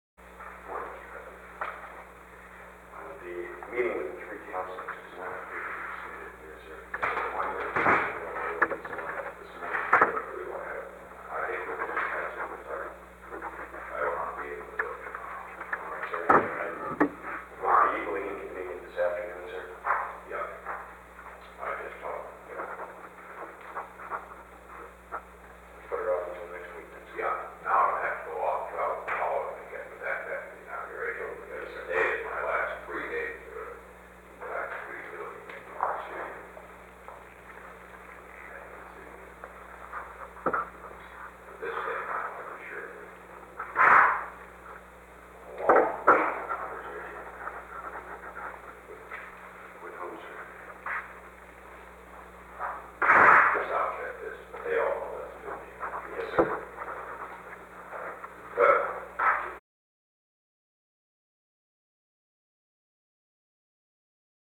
Conversation: 838-001
Recording Device: Oval Office
The Oval Office taping system captured this recording, which is known as Conversation 838-001 of the White House Tapes.